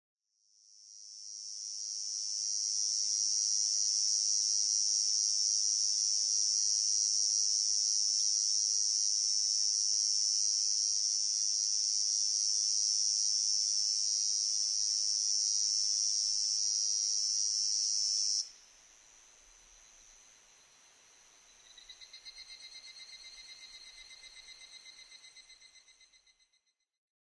コエゾゼミ　Tibicen bihamatusセミ科
日光市稲荷川中流　alt=950m  HiFi --------------
Mic.: audio-technica AT825
他の自然音：　 ヒグラシ